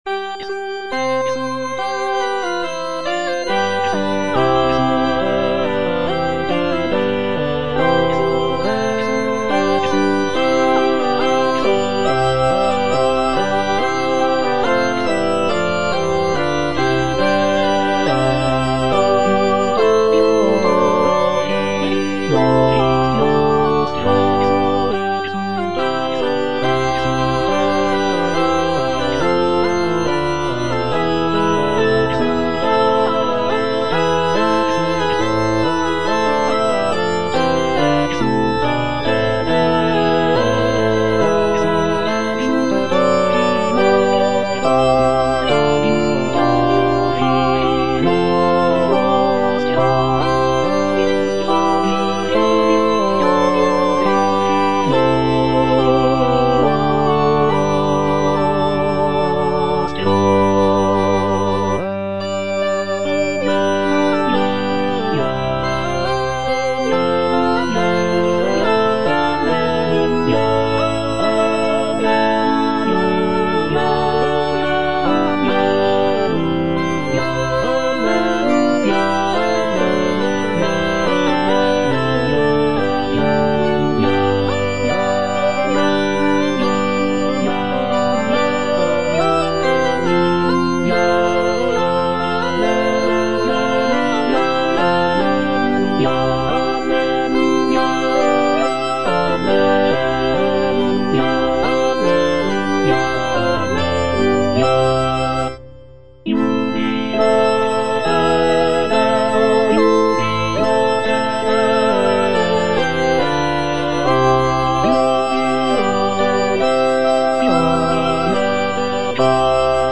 (All voices) Ads stop